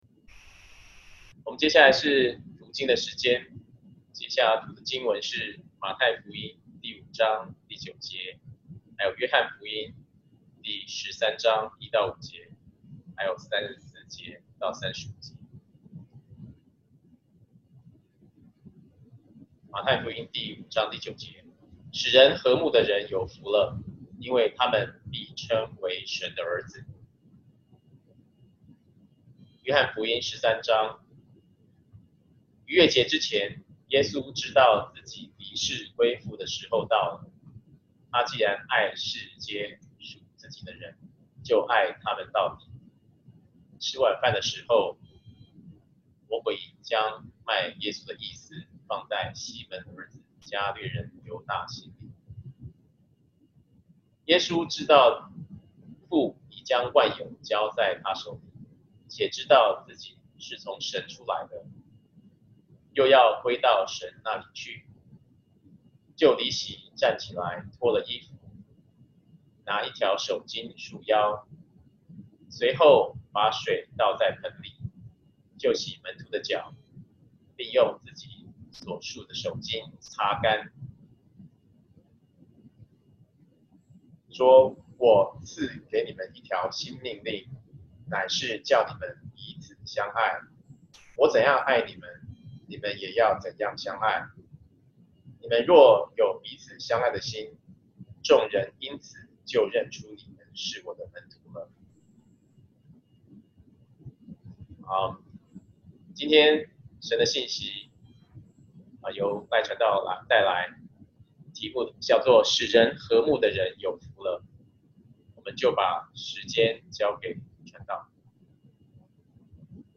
Bible Text: 馬太福音5:9 約翰福音13:1-5, 34-35 | Preacher